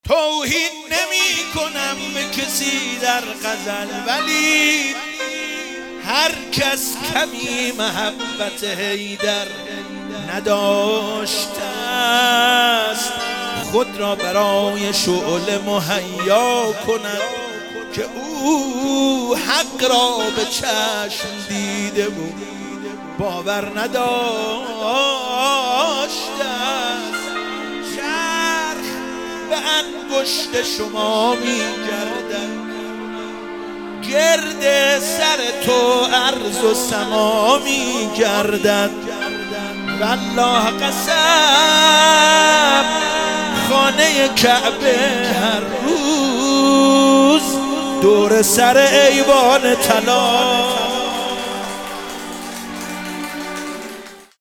عید سعید غدیر